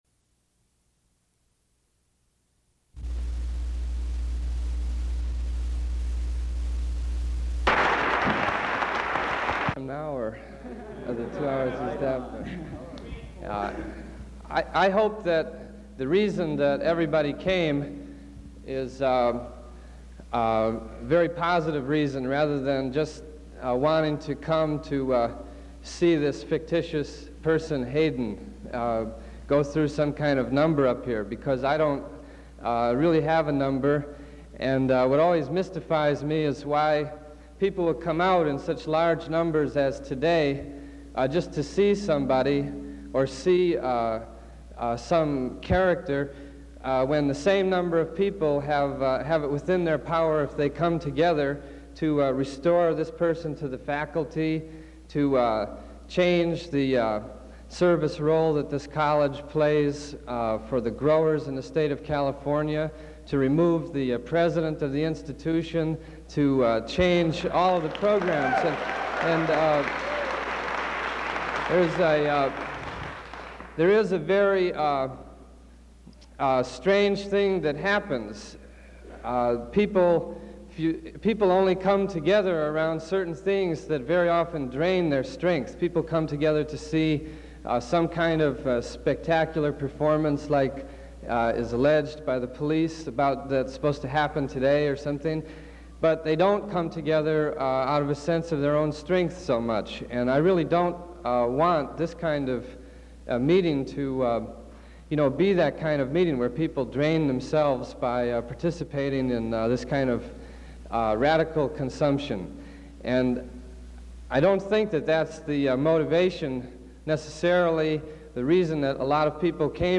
Tom Hayden, SNAP Meeting, May 4, 1971 [reels 1 and 2]
Form of original Audiocassette